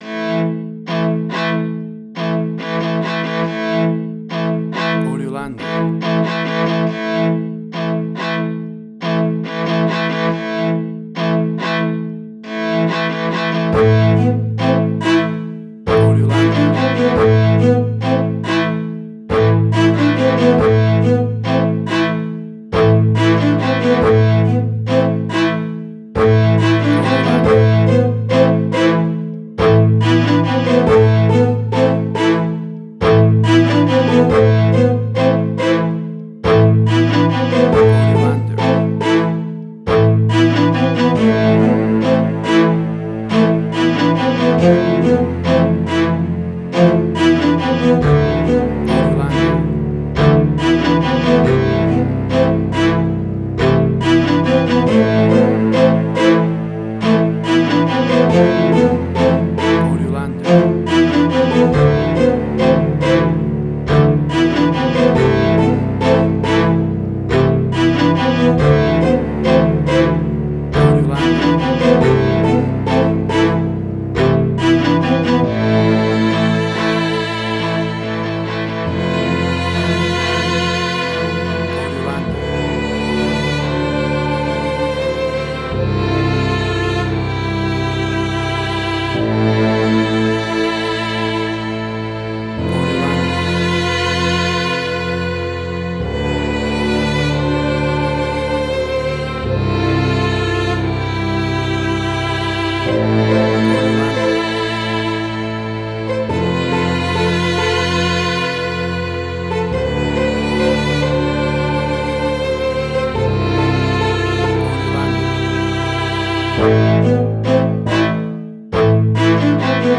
Spaghetti Western